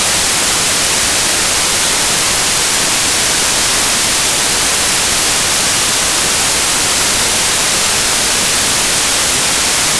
whitenoise.wav